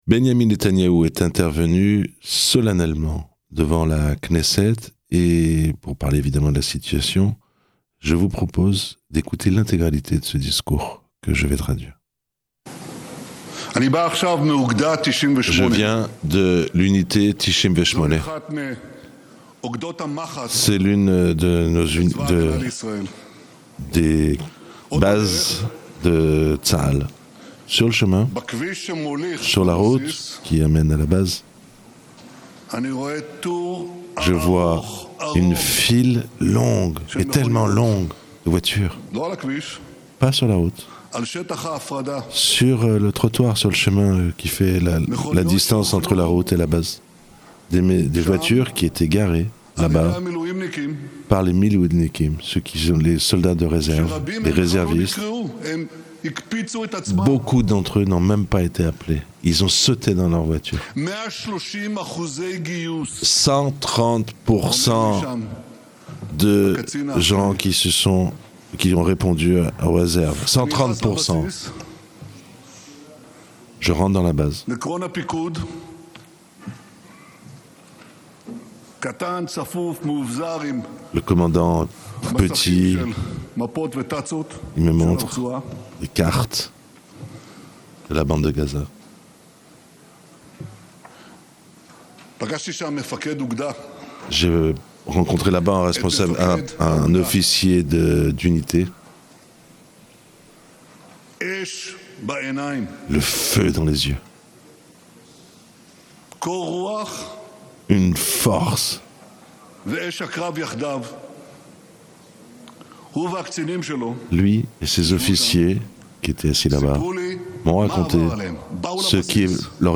Ecoutez le discours historique de Netanyahou traduit en français